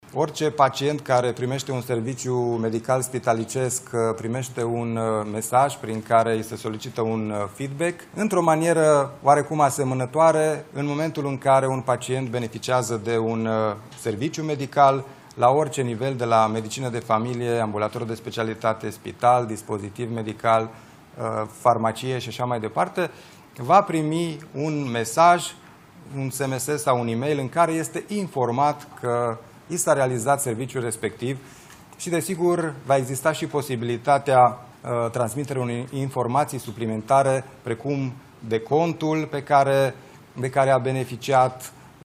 Declarațiile au fost făcute în conferința de presă maraton de ieri în care premierul Ilie Bolojan și ministrul sănătății – Alexandru Rogobete – au prezentat o parte din măsurile care vor fi luate pentru reforma sistemului de sănătate.